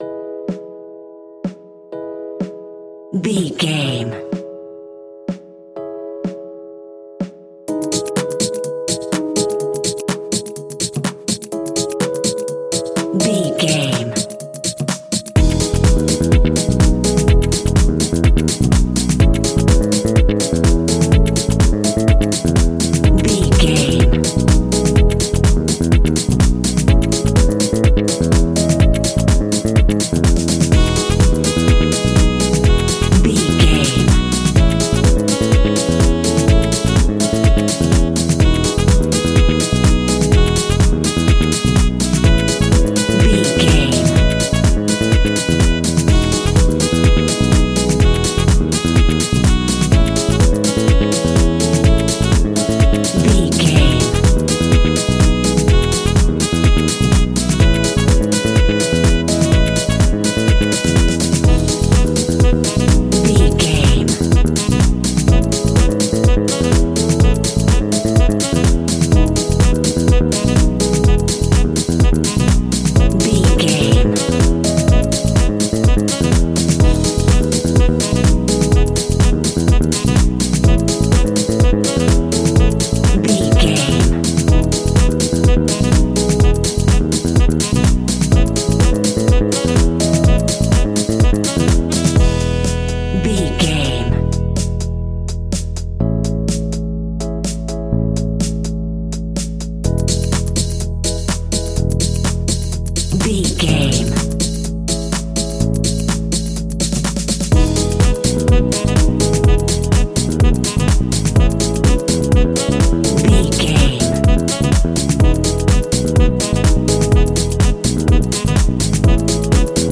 Ionian/Major
uplifting
energetic
bouncy
bass guitar
saxophone
electric piano
drum machine
disco
groovy
upbeat
funky guitar
synth bass